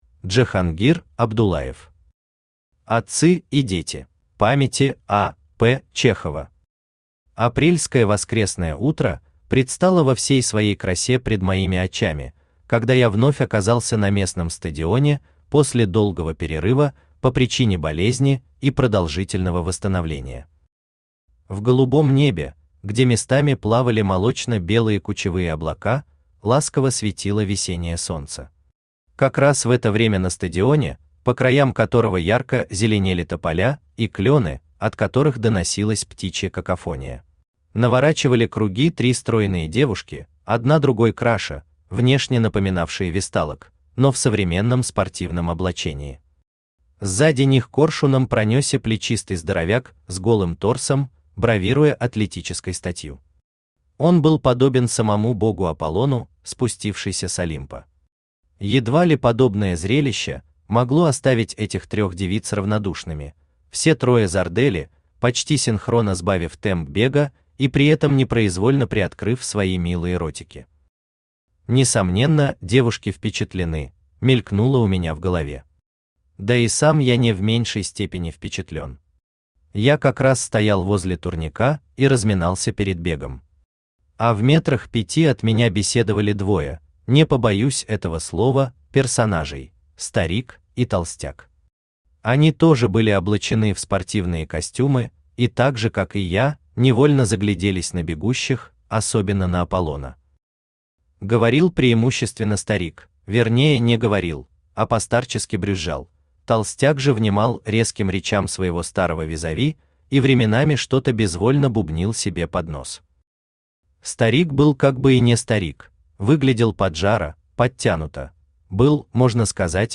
Aудиокнига Отцы и дети Автор Джахангир Каримджанович Абдуллаев Читает аудиокнигу Авточтец ЛитРес.